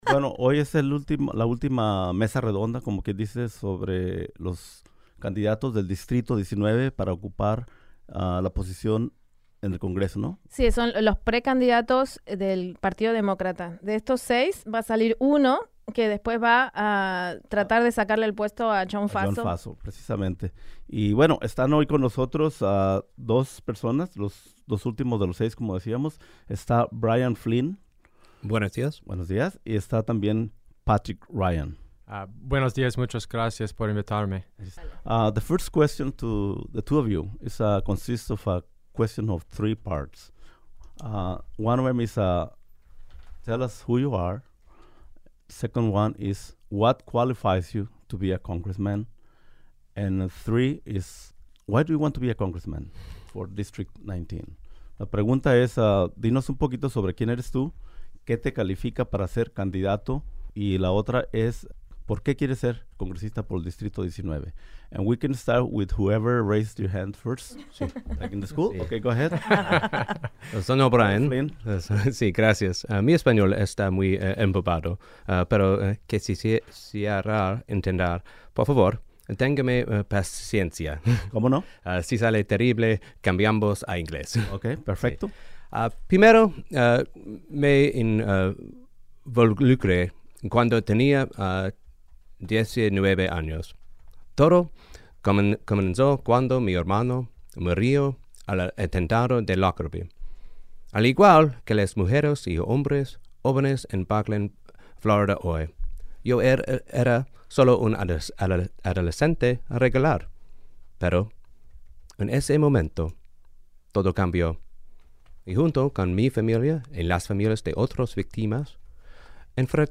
In a bilingual interview about immigration, police brutality, spying, job creation, and foreign policy, among other topics.